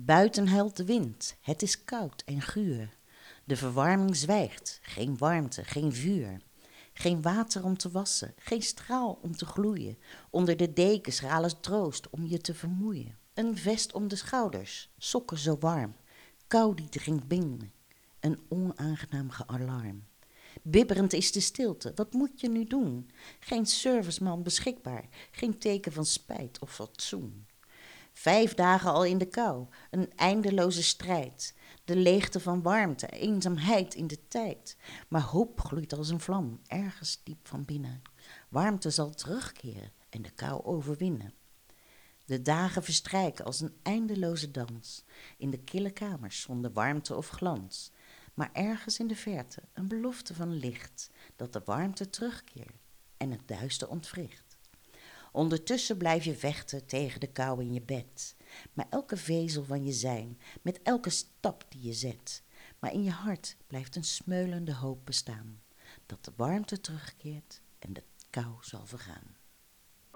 Dit keer is de titel van het gedicht, hoe kan het anders het is winter "koud".Kletskoek wordt iedere vrijdagochtend van 10 uur tot 13 uur "live" vanuitde studio van Radio Capelle wordt uitgezonden.